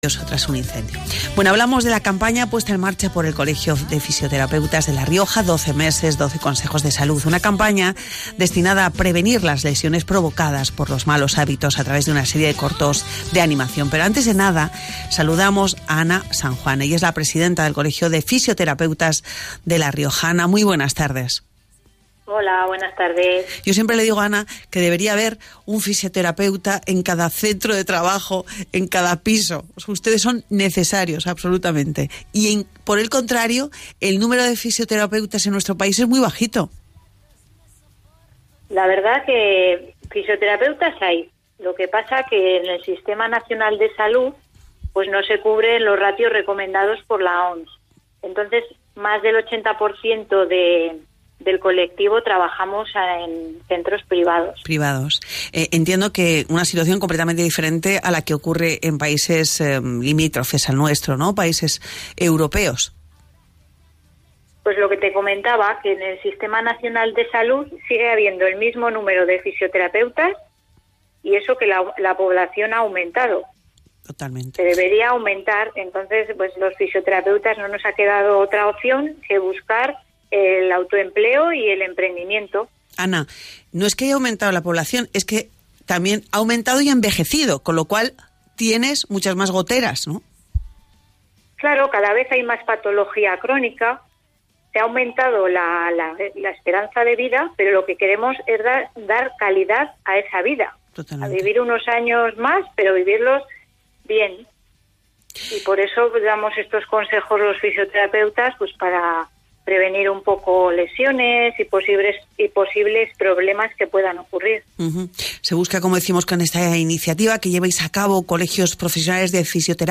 Pincha sobre las imágenes de los diferentes medios de comunicación para ver y/o escuchar las entrevistas.